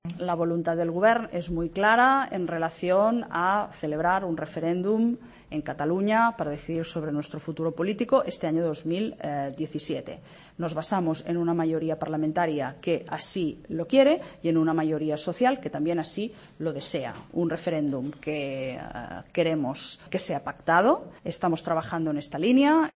La portavoz del Gobierno catalán, Neus Munté, acusa al Gobierno de Rajoy de radicalización por negarse a negociar la consulta